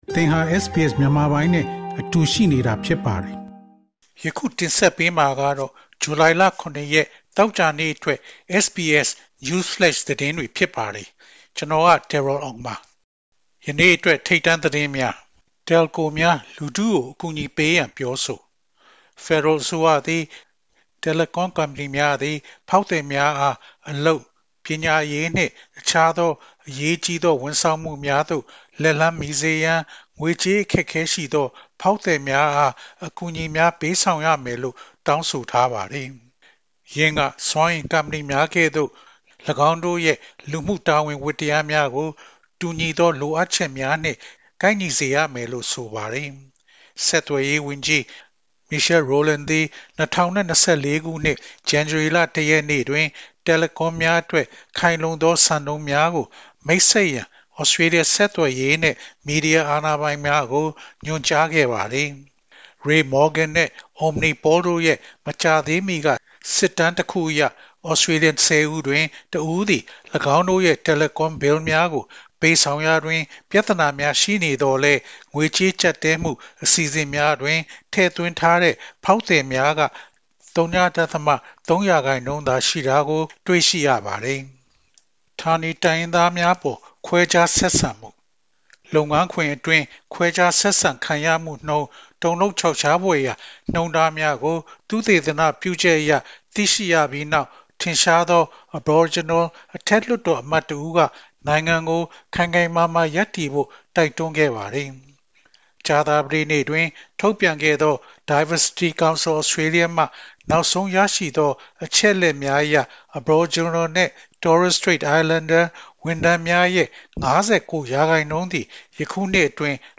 SBS Burmese Evening Newsflash